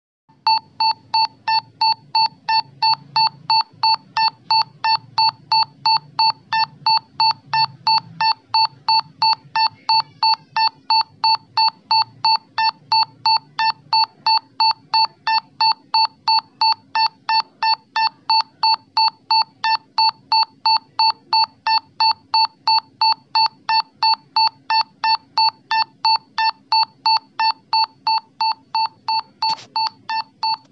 我们将 TAS2505 DAC 用于声音， 在播放具有一些暂停值的声音时，我们观察到了一些声音振幅变化。
总音频播放时间为100ms、然后是200ms 的暂停时间。
当振幅减小时、声音看起来会发生位变。
采样率为22Kz。